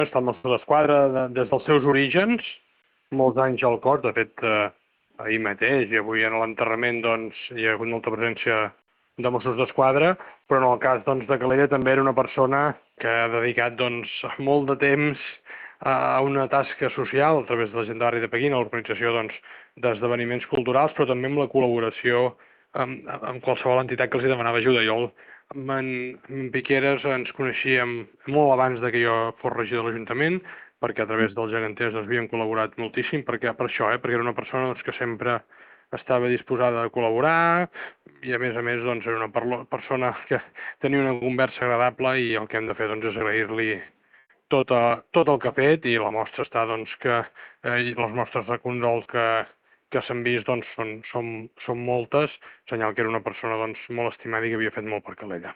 Entre elles, la de l’alcalde de Calella, Marc Buch, qui en declaracions a Ràdio Calella TV ha destacat el seu compromís amb la ciutat.